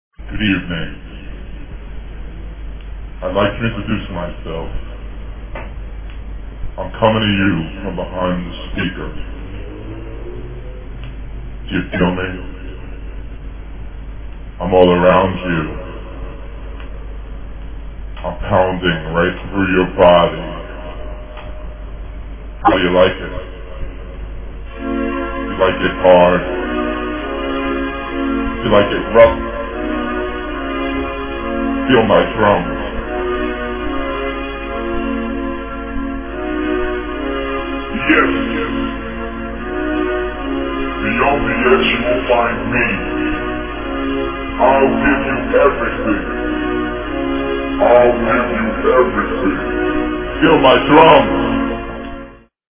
acapella...
and the music at the end?